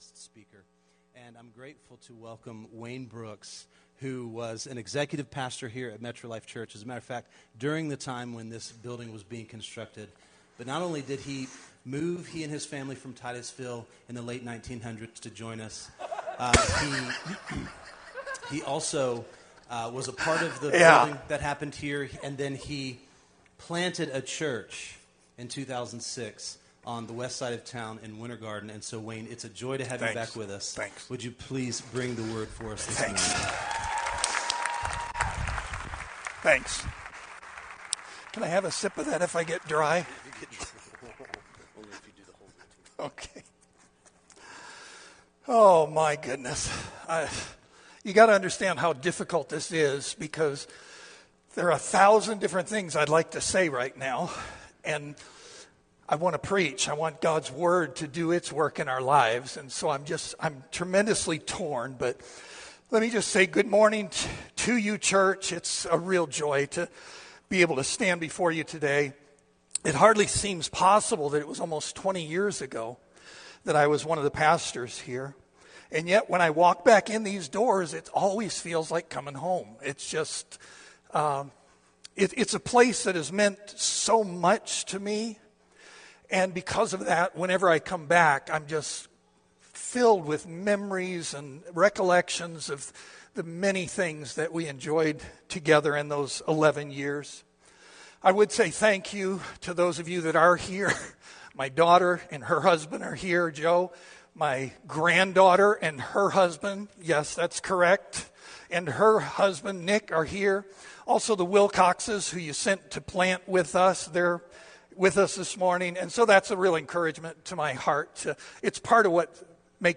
Metro Life Church Sermons